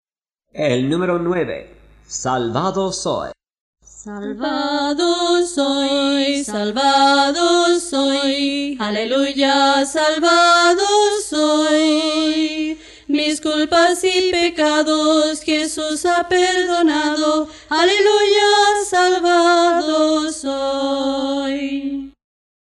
Las melodías usadas corresponden a la música original.